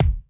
keys_22.wav